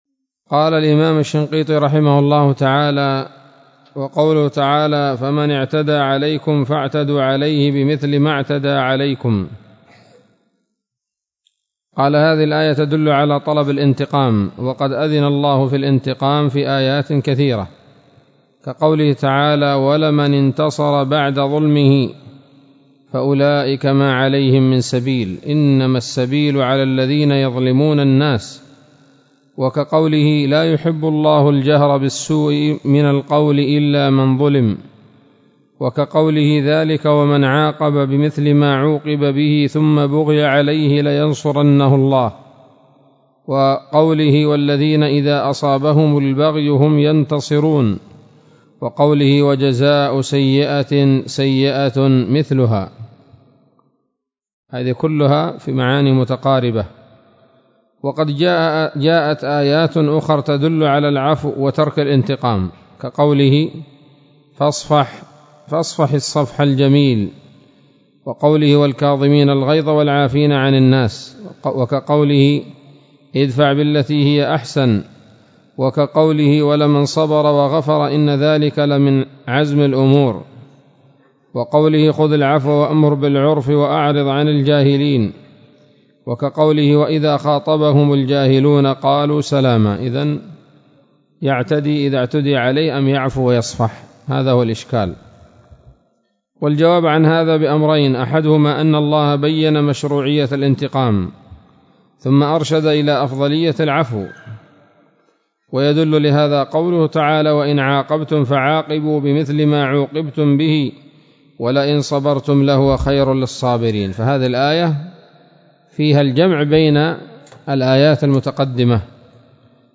الدرس الثامن عشر من دفع إيهام الاضطراب عن آيات الكتاب